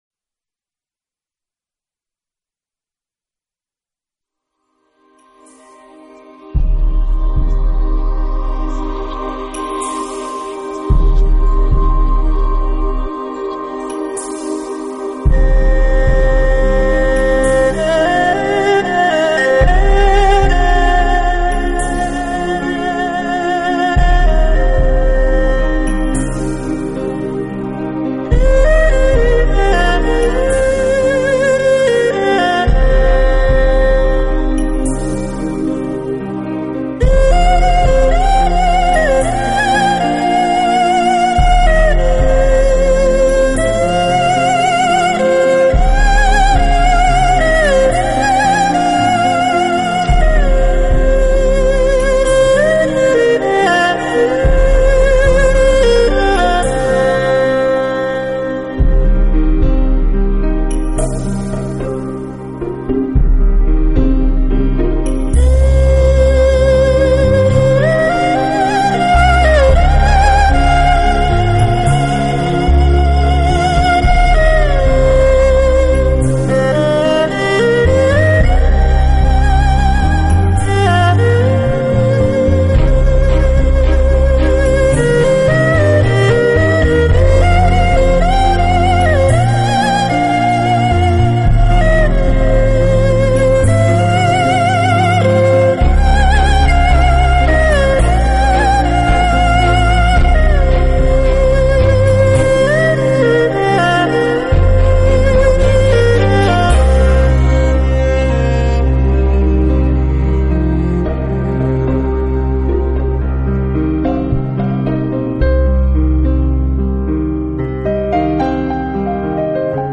一張中西樂合壁的專輯
和加上音效後, 為樂迷送上很大驚喜。